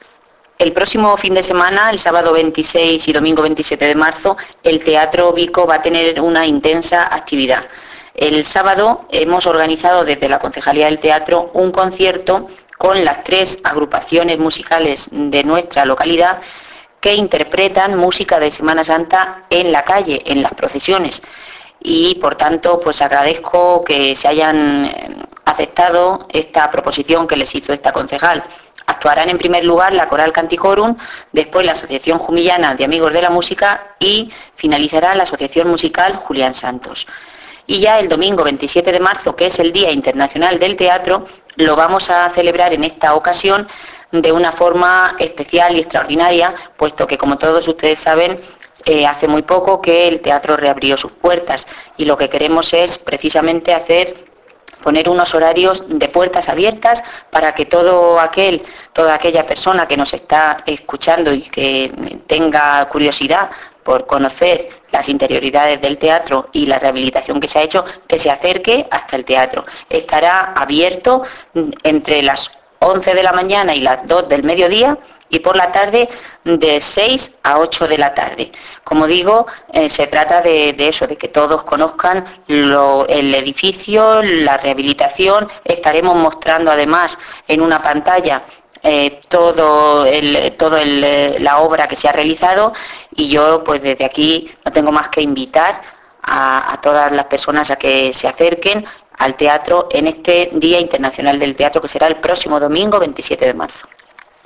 Descargar: Juana Guardiola habla de estas actividades subir